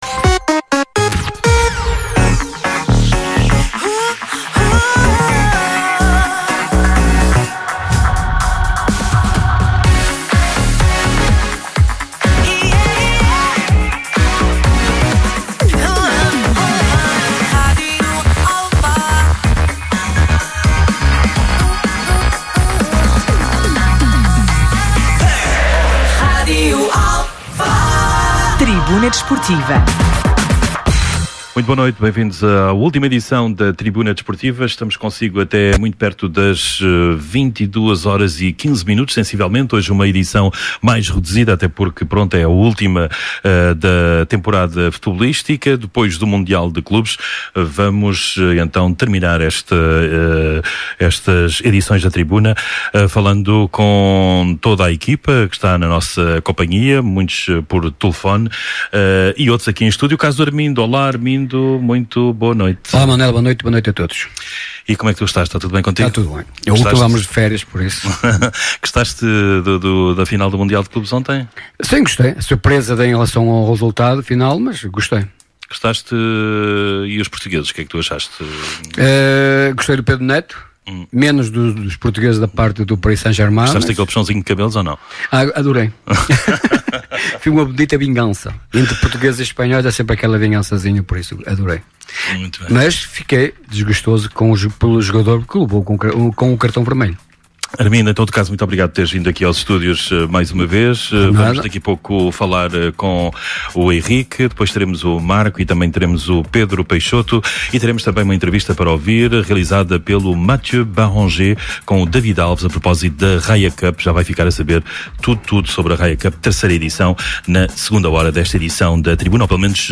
Atualidade, Entrevistas, Debate.
Tribuna Desportiva é um programa desportivo da Rádio Alfa às Segundas-feiras, entre as 21h e as 23h.